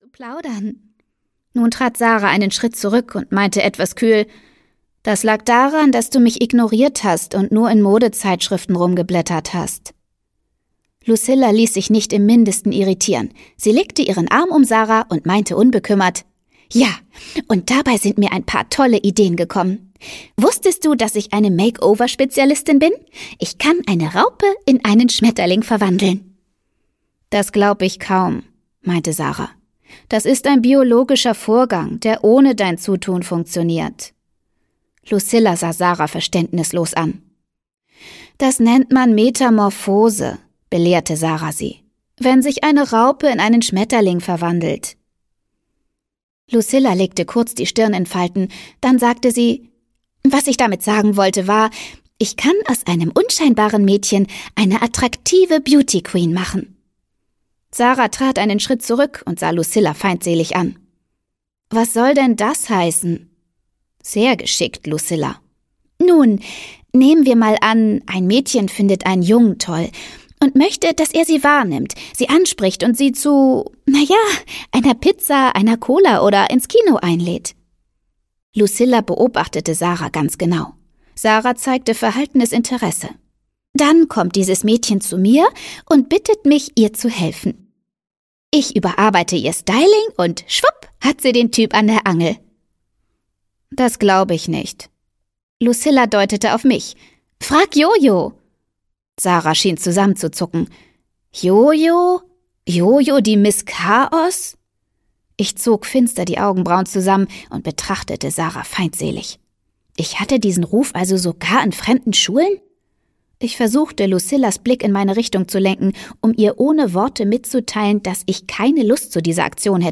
Freche Mädchen: Liebe, Schuss, Elfmeterkuss - Hortense Ullrich - Hörbuch